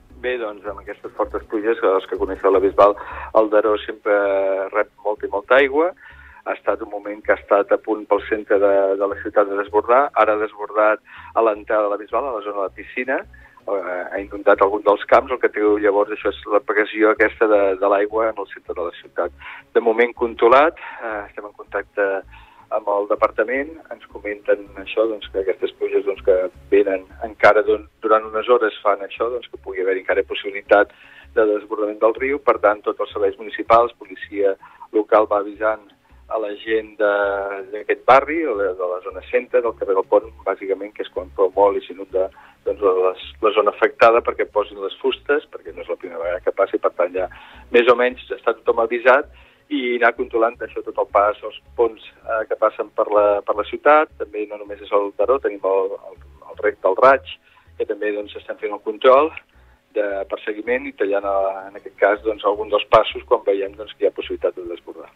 8:40h – Estat actual del rius al Baix Empordà: Daró a la Bisbal d’Empordà a 510 m³/s, Daró a Serra de Daró a 201 m³/s. Ter a Colomers a 454 m³/s, Ter a Torroella de Montgrí a 317 m³/s. Ridaura a Santa Cristina d’Aro a 25,7 m³/s. L’alcalde de la Bisbal d’Empordà, Òscar Aparicio, explicava al Supermatí del risc de desbordament del riu Daró en el seu pas pel municipi.